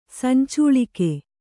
♪ sancūḷike